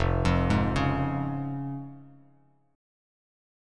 Fm#5 chord